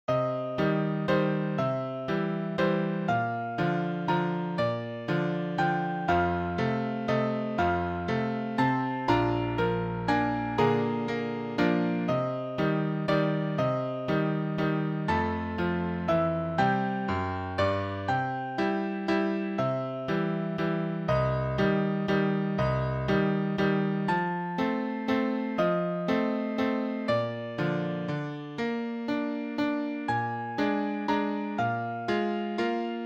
pi.mp3 (zongora) pi mbox.mp3 (csemball�) pi-song.mp3 (harmonika + �nek) (R�szletes le�r�s tal�lhat� a K�dt�r� ABC 6.6 fejezet 125. oldal�n.)